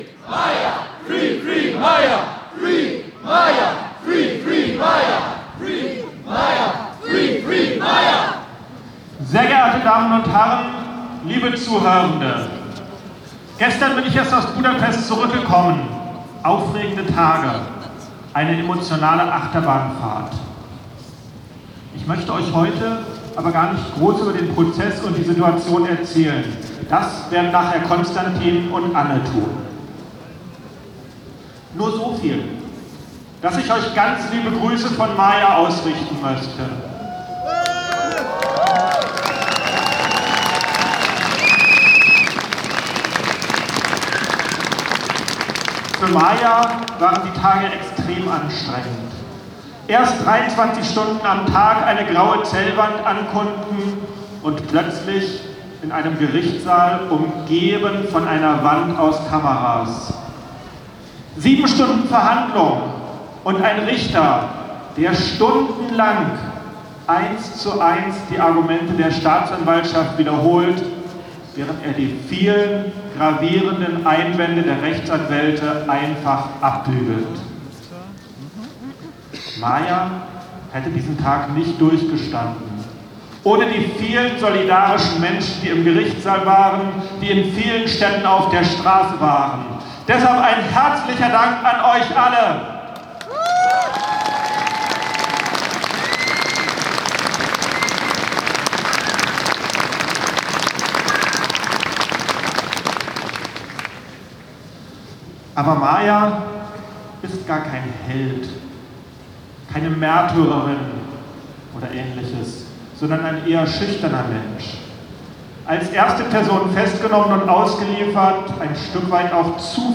Zwischendurch kurze Demosprüche-Aufnahmen von der Laufdemo.